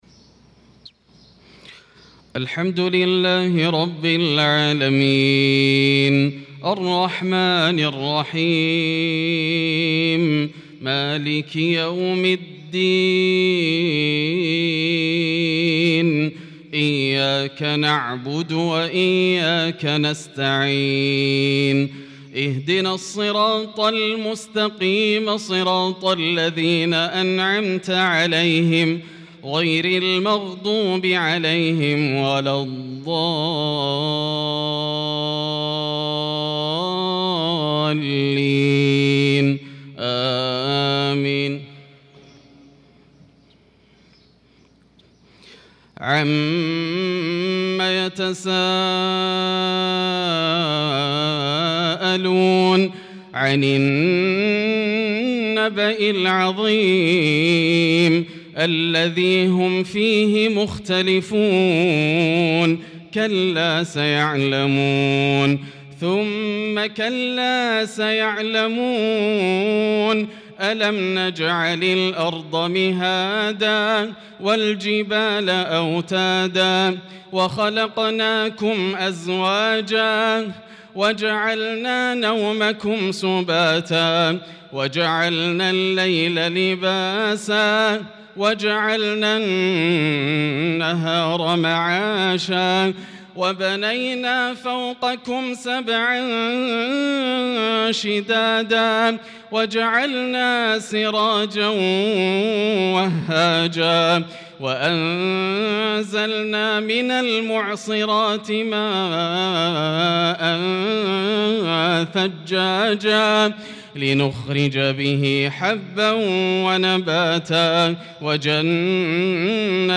صلاة الفجر للقارئ ياسر الدوسري 5 شوال 1445 هـ